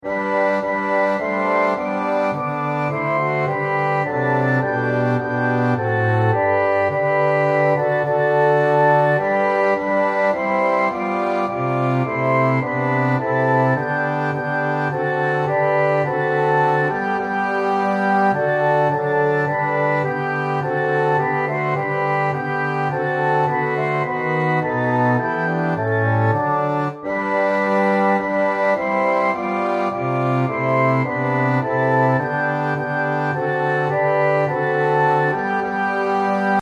Hymns of praise
Orchestral Version